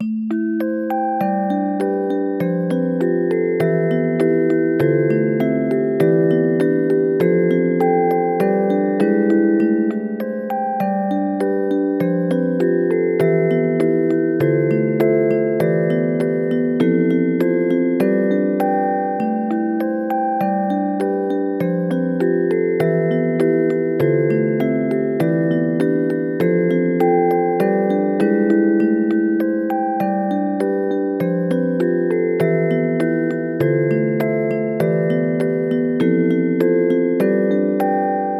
少しバロック風味のオルゴール曲です　パイプオルガンVer.